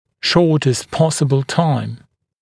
[‘ʃɔːtəst ‘pɔsəbl taɪm][‘шо:тэст ‘посэбл тайм]кратчайшие сроки